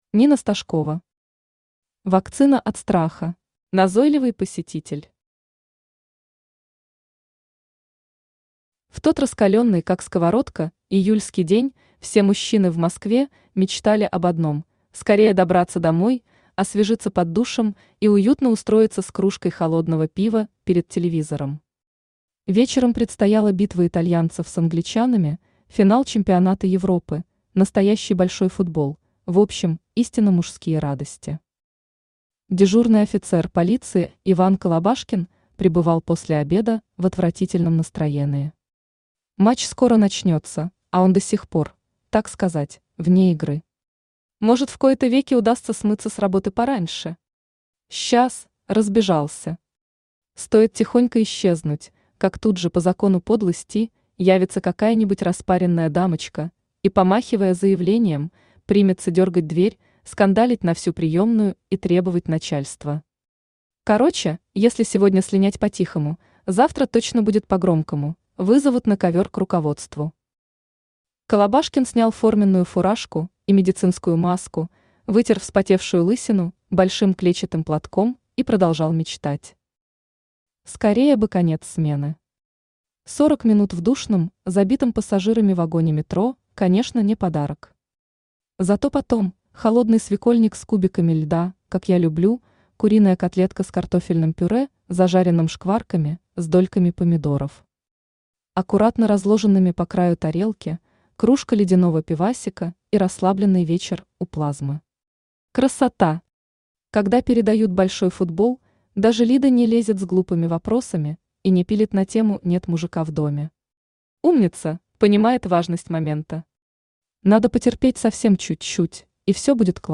Аудиокнига Вакцина от страха | Библиотека аудиокниг
Aудиокнига Вакцина от страха Автор Нина Стожкова Читает аудиокнигу Авточтец ЛитРес.